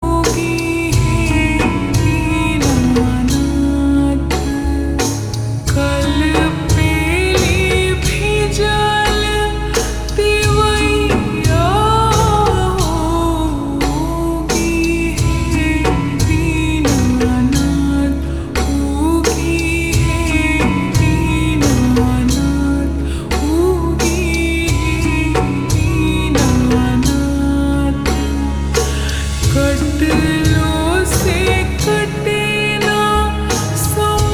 Bhojpuri Songs
Slow Reverb Version
• Simple and Lofi sound